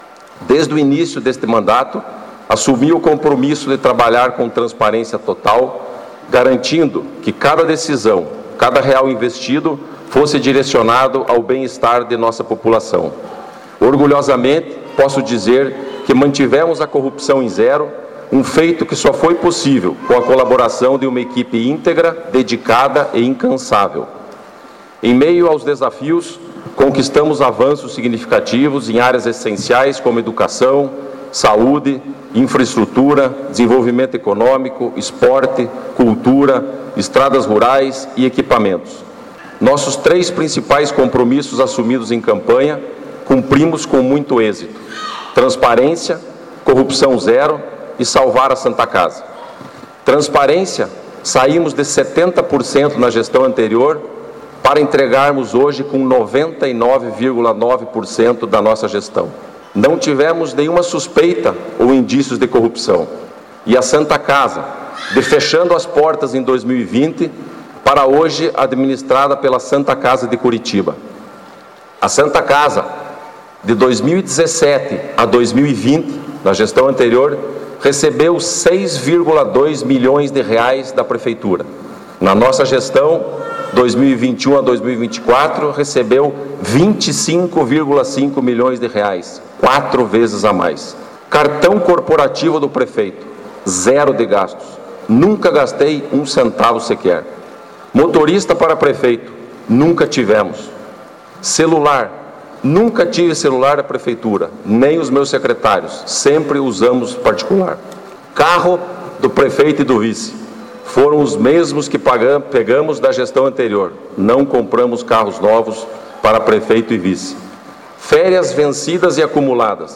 Na manhã do primeiro dia de 2025, no Cine Teatro Municipal, com lotação completa, aconteceu sessão solene de posse dos eleitos em 6 de outubro para a Prefeitura e a Câmara Municipal de Palmeira.
O ex-prefeito Sérgio Belich também falou, fazendo uma explanação sobre realizações de sua administração e terminando com a entrega ao prefeito Altamir Sanson de documentos oficiais e o projeto de melhorias do Distrito Industrial, além da entrega simbólica das chaves da Prefeitura.